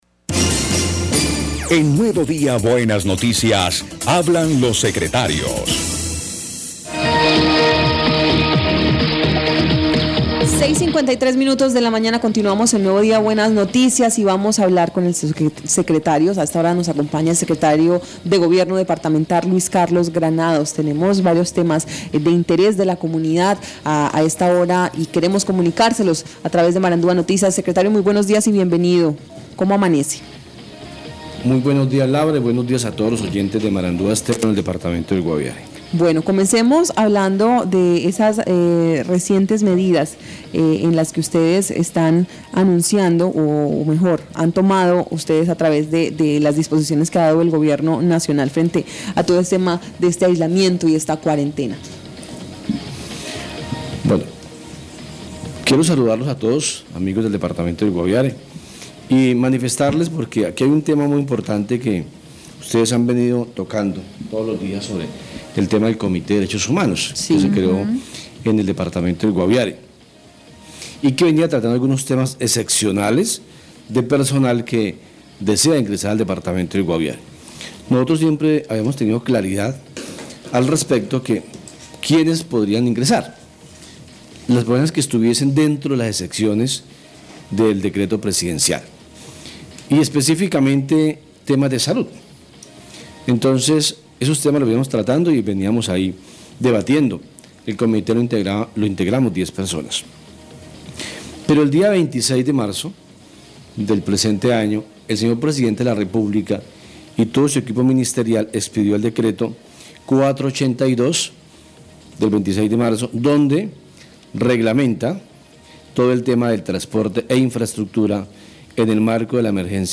Escuche a Luis Carlos Granados, secretario de Gobierno del Guaviare.